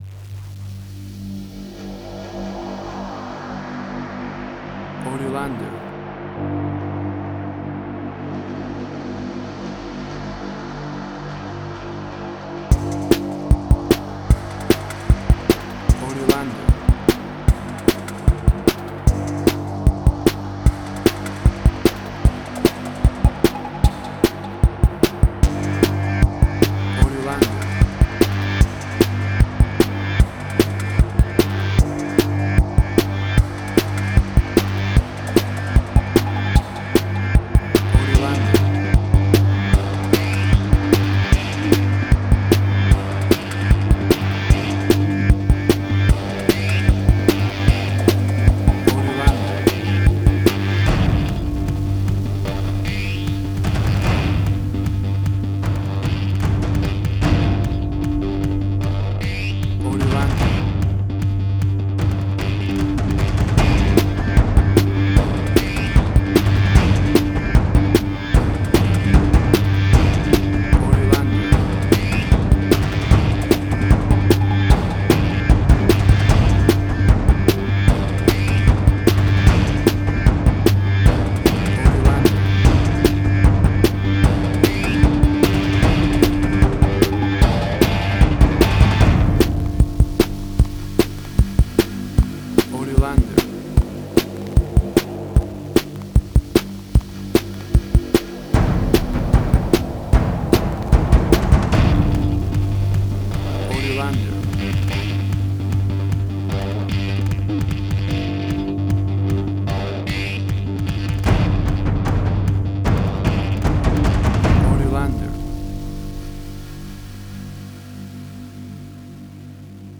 Suspense, Drama, Quirky, Emotional.
WAV Sample Rate: 16-Bit stereo, 44.1 kHz
Tempo (BPM): 151